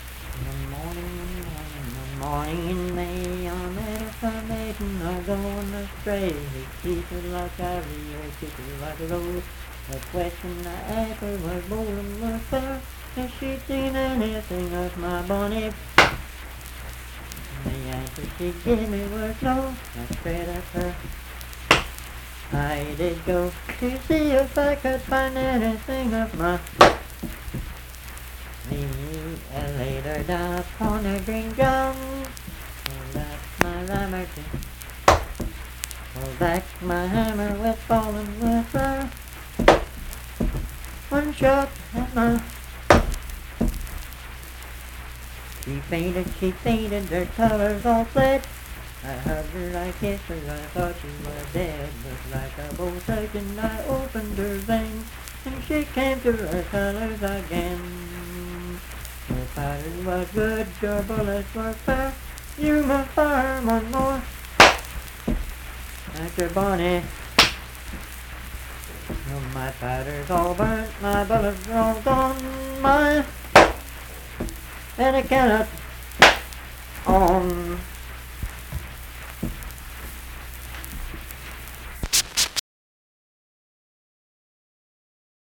Unaccompanied vocal music
Verse-refrain 5(2-5). Performed in Dundon, Clay County, WV.
Bawdy Songs
Voice (sung)